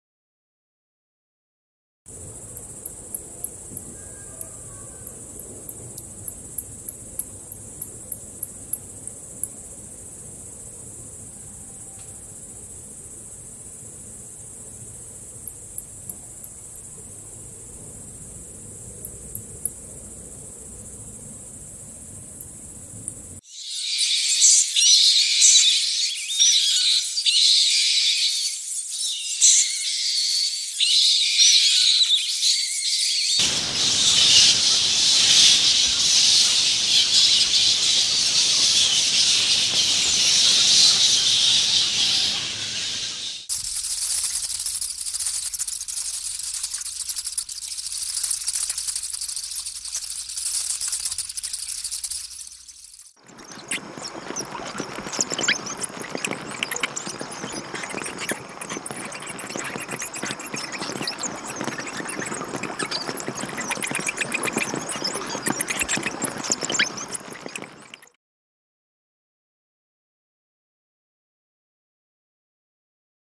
Bat Chirping Sounds Botón de Sonido
Animal Sounds Soundboard133 views